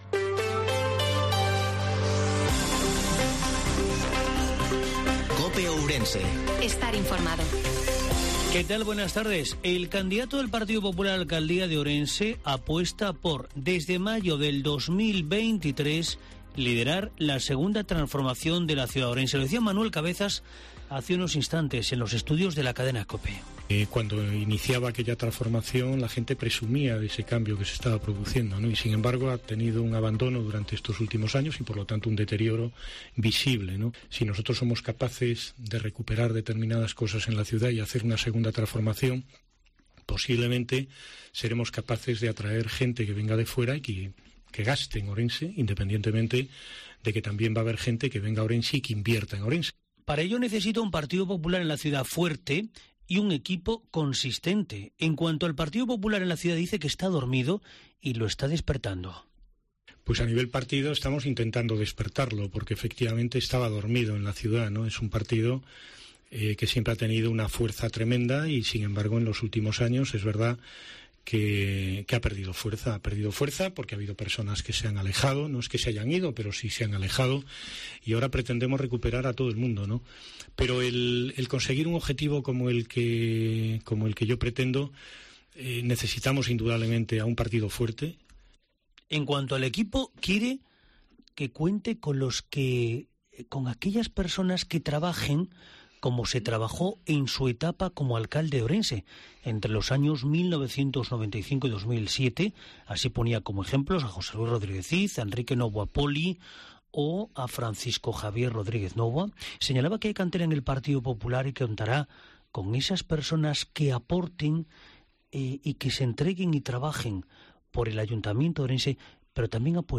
INFORMATIVO MEDIODIA COPE OURENSE-14/09/2022